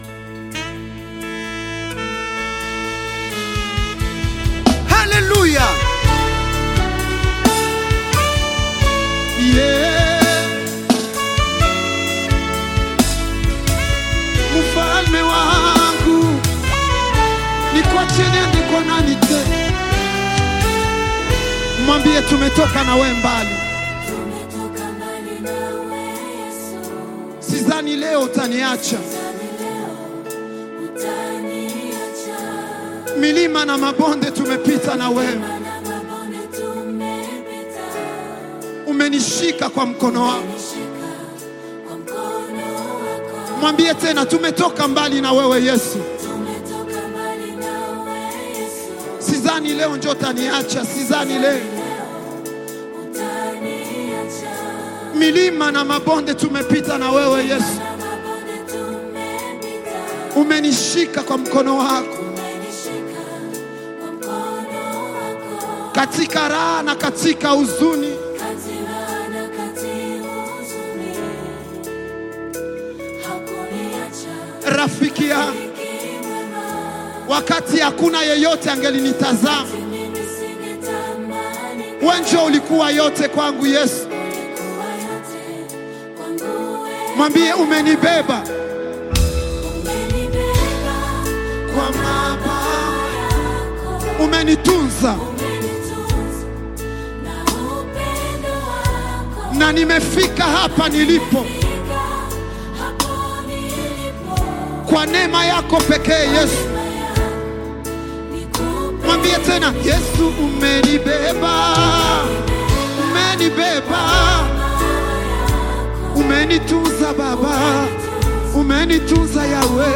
African Music
Gospel singer and songwriter
gospel song